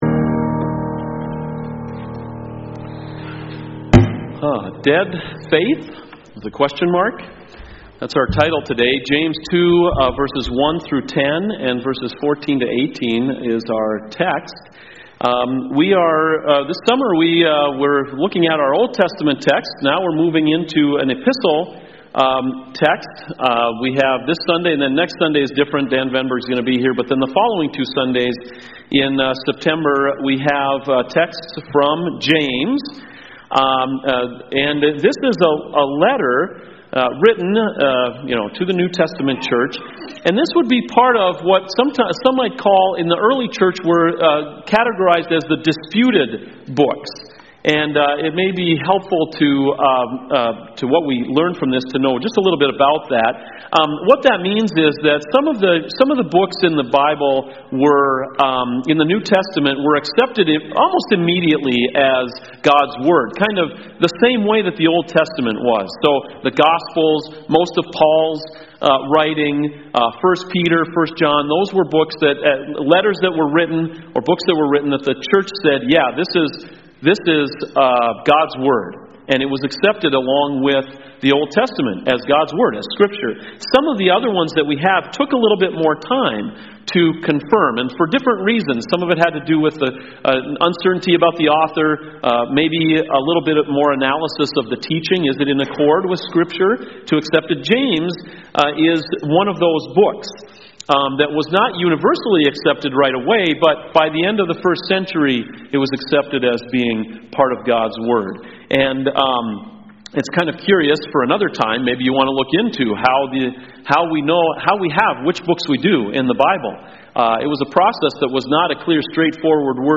CoJ Sermons Dead Faith?